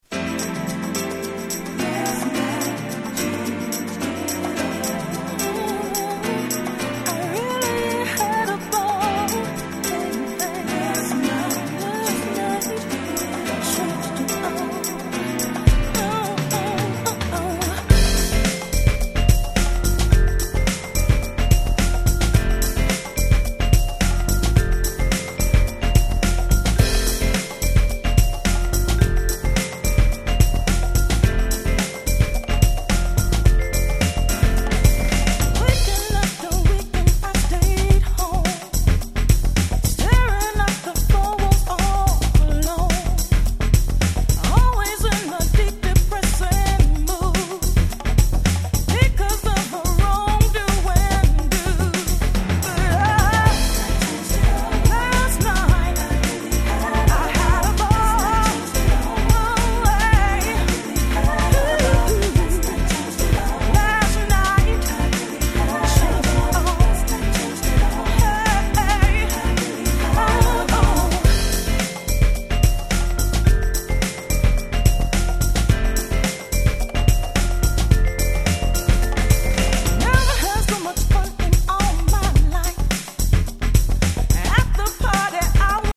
知る人ぞ知る、素晴らしいカバー！！
そのパワフルでSoulfulなVocalが最高に気持ち良い！！
UK物やEurope物、はたまたダンクラなんかともうまく混ざる、めちゃくちゃ使える1枚！！